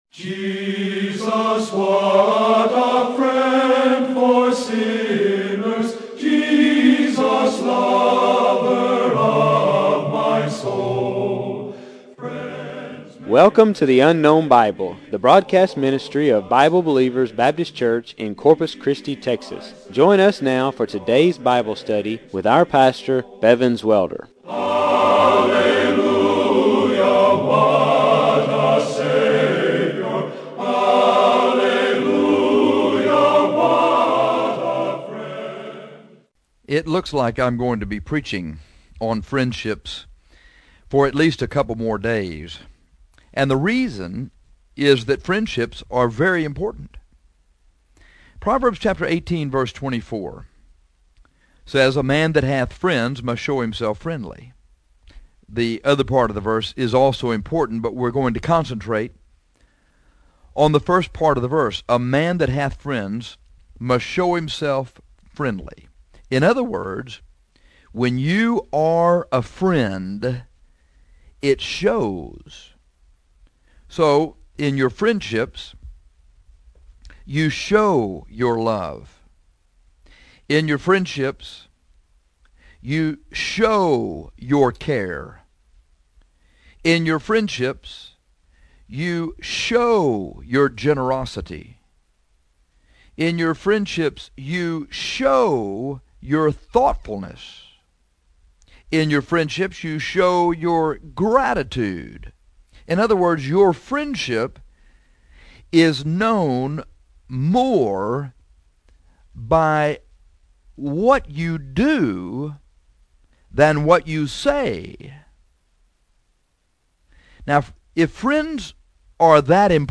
This sermon is about how to make friends. If you will follow these five points, you can become a good friend.